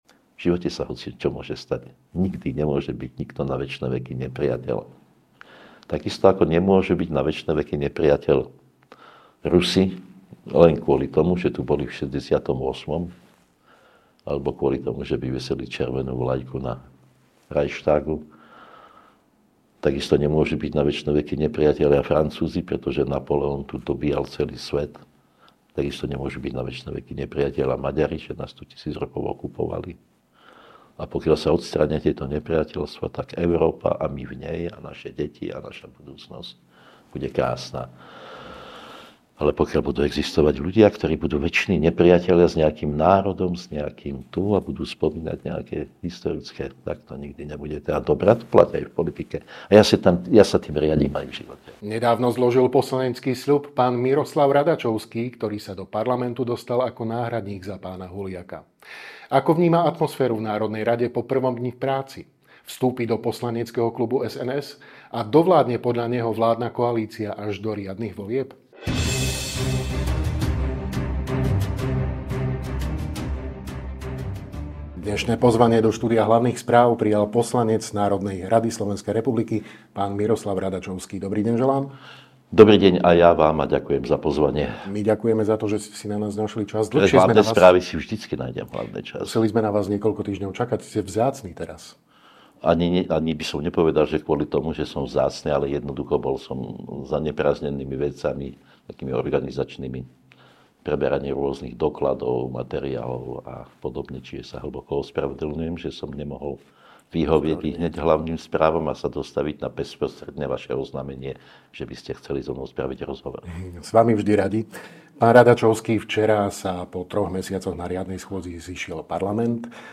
Viac vo videorozhovore.